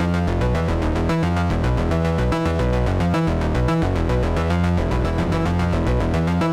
Index of /musicradar/dystopian-drone-samples/Droney Arps/110bpm
DD_DroneyArp1_110-E.wav